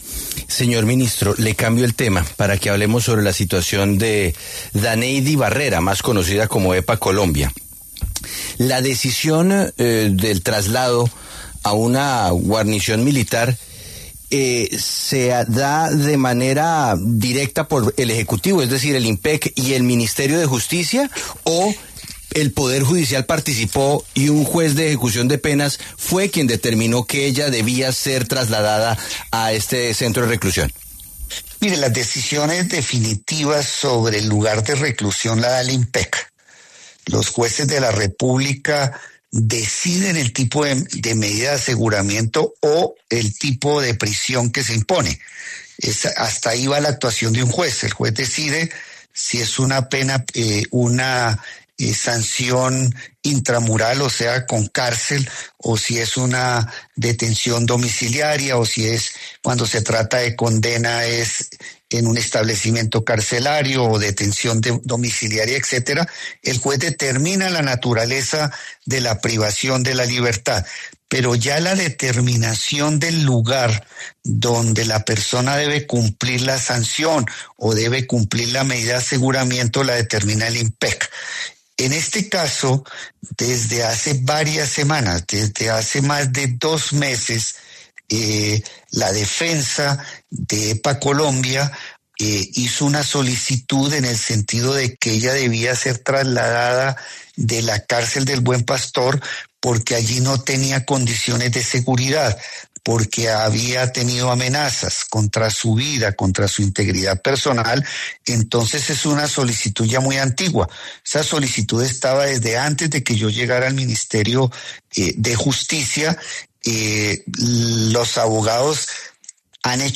Eduardo Montealegre, ministro de Justicia, pasó por los micrófonos de La W y se refirió al traslado de Daneidy Barrera Rojas, ‘Epa Colombia’.